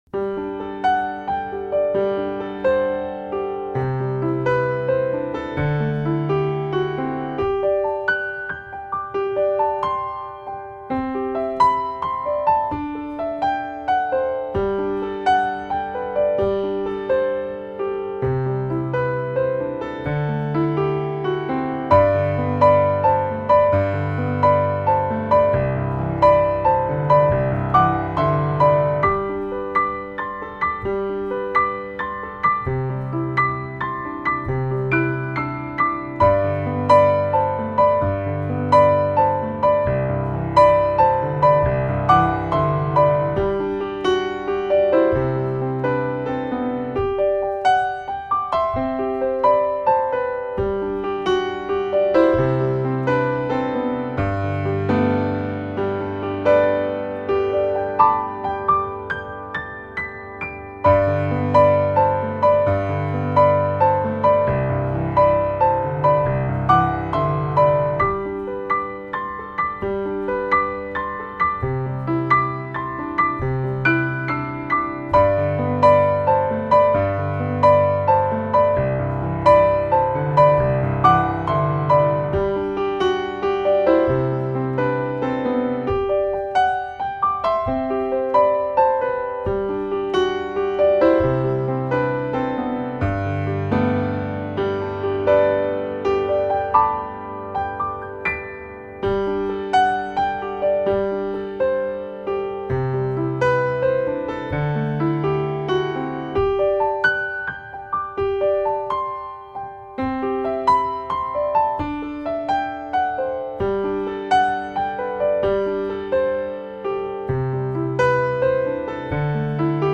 主奏乐器：钢琴
★都会自然风钢琴大师
即使在乔治温斯顿的音乐中，也难有这般清透的音色